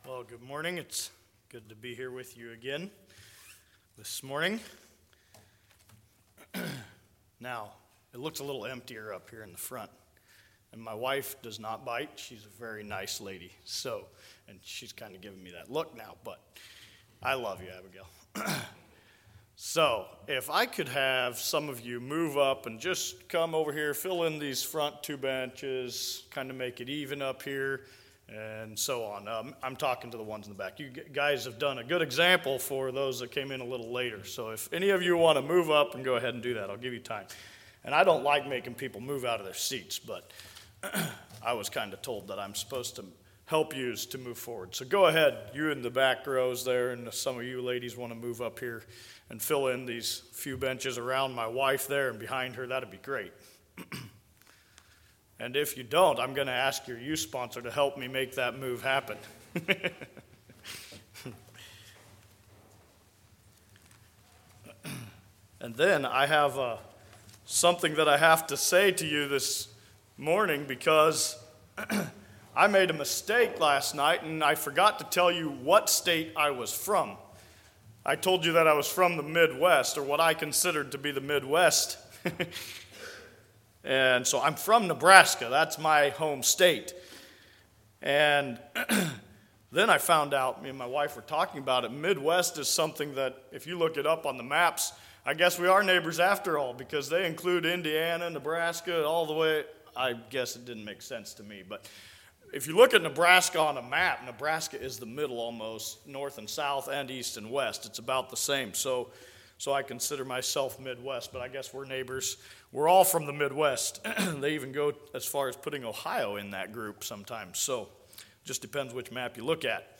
Sermon
Youth Rally 2025 Like this sermon?